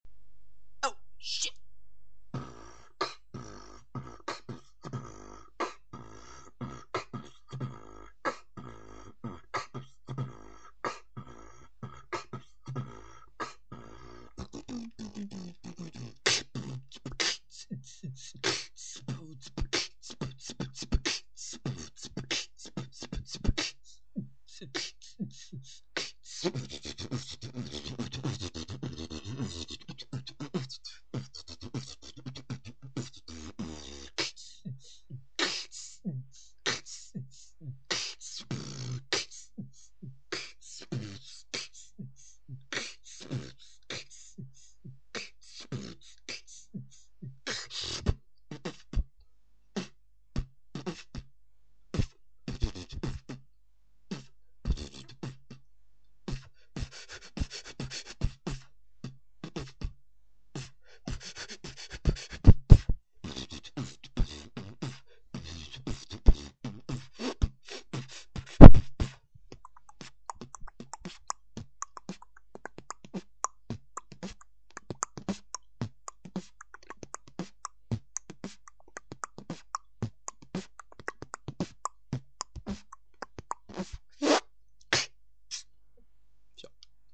Фристайл
Вообщем запись как обычно на палочку, не судите строго cool
1. Биток простенький, думаю за 5 месяцев можно было поярче освоить, но все же это только одна запись, кто знает, что ты там умеешь.
ещё я либо вообще не услышал перебитовок, либо их было очень мало, в остальном терпимо)